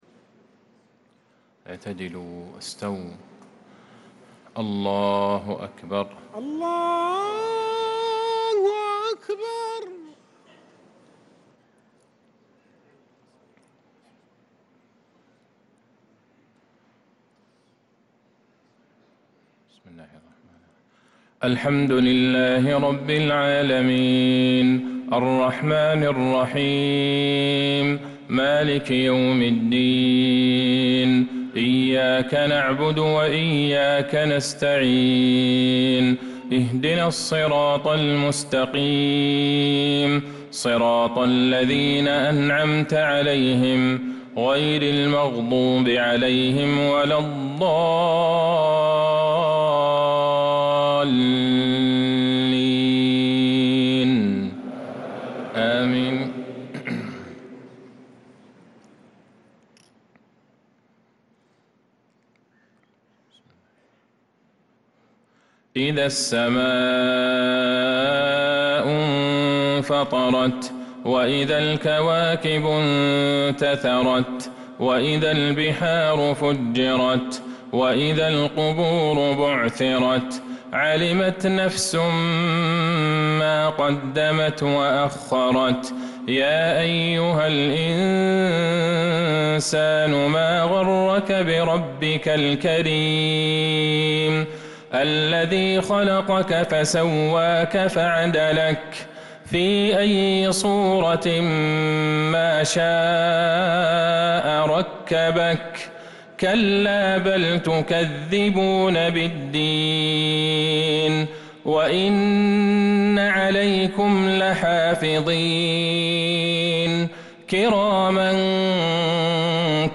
عشاء السبت 10 محرم 1447هـ سورتي الإنفطار و الطارق كاملة | Isha prayer from Surah Al-Infitaar and At-Tariq 5-7-2025 > 1447 🕌 > الفروض - تلاوات الحرمين